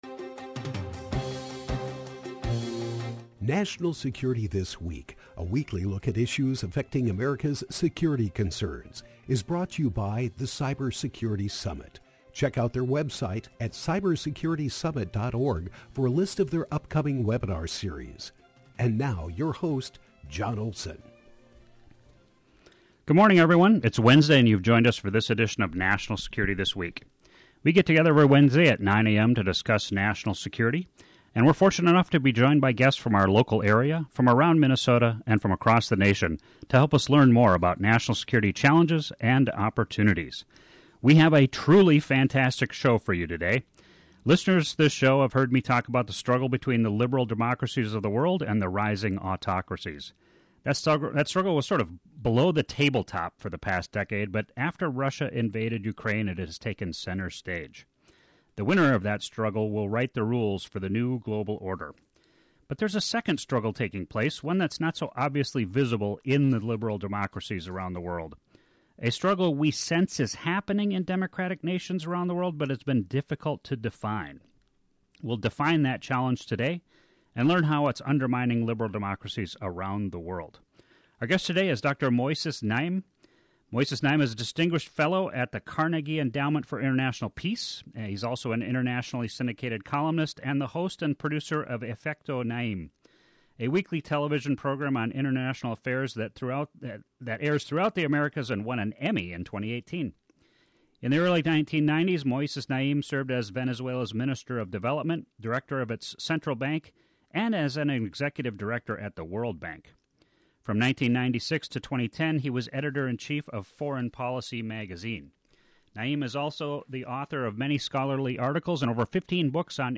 National Security This Week with Dr. Moises Naim, 12-14-22 – KYMN Radio · Northfield, MN · AM 1080 & FM 95.1